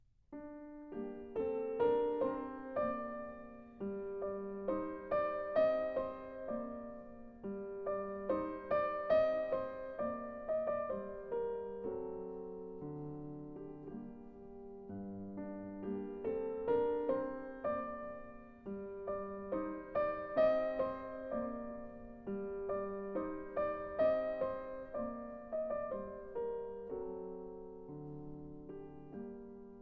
Фрагмент «Детского альбома» (Минор)